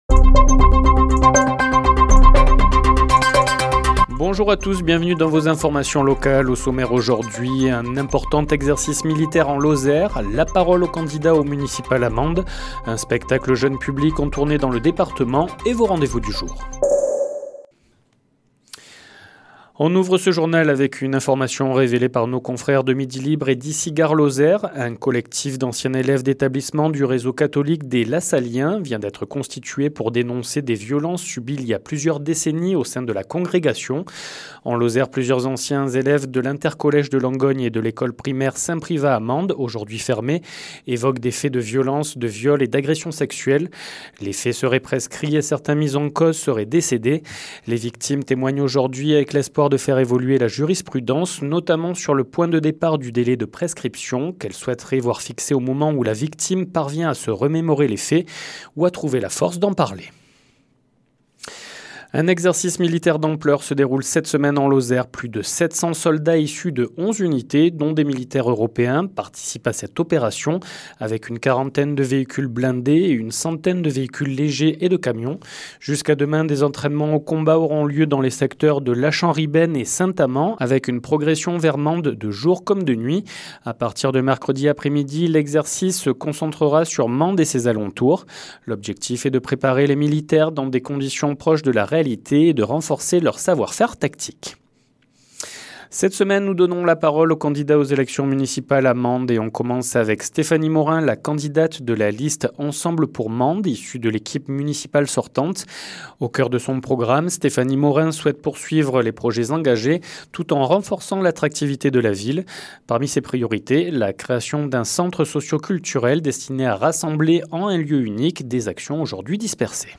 Le journal sur 48FM
Les informations locales